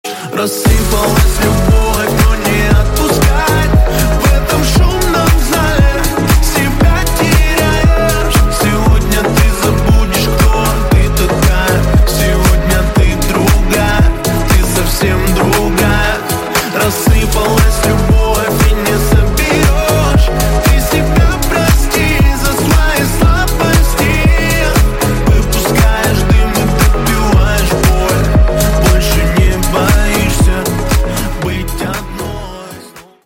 • Качество: 128, Stereo
грустные